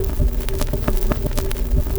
TURNTABLE.wav